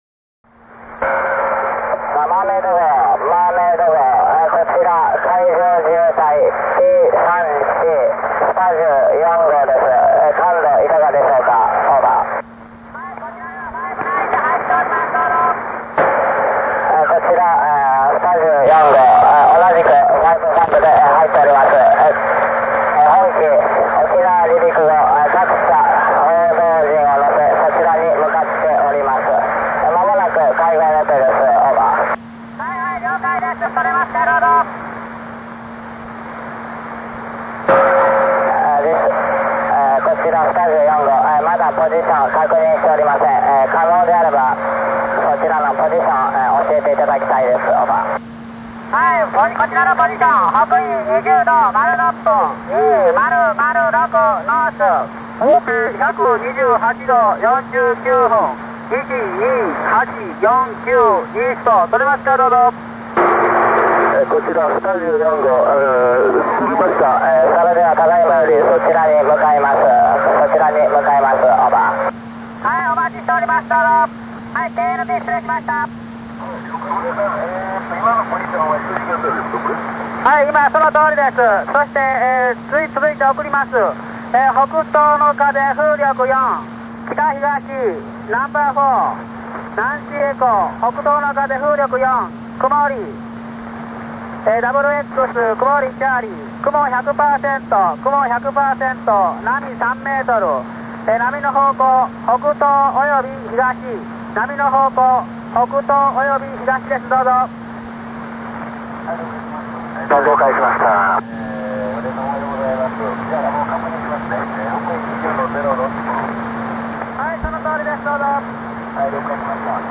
その一人にナント海上自衛隊の対潜哨戒機 P3C の機長さんもいたことを知る人は少ないでしょう。詳しい日時などは差し控えますが、その録音抜粋を今回ご紹介します。